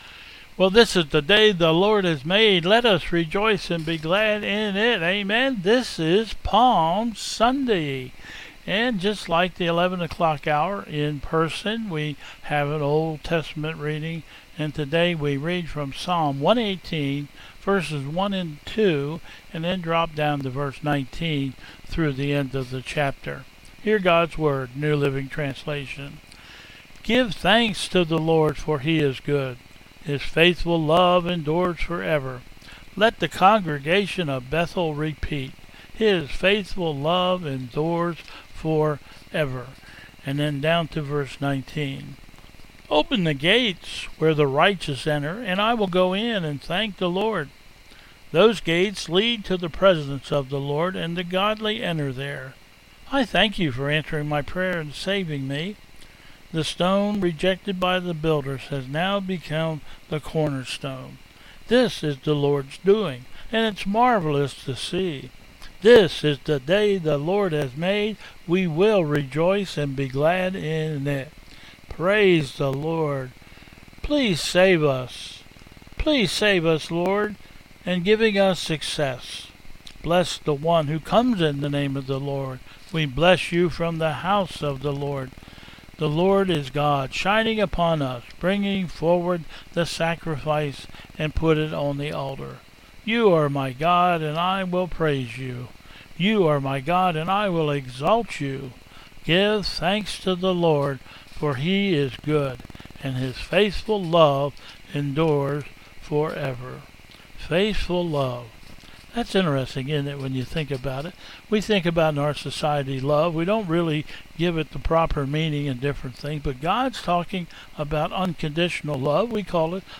Processional